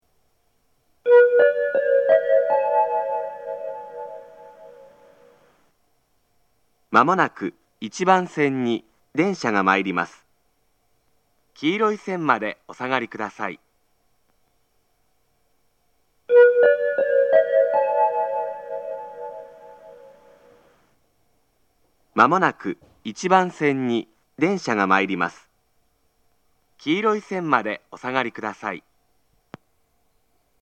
仙石旧型（男性）
接近放送
仙石旧型男性の接近放送です。同じ内容を2度繰り返します。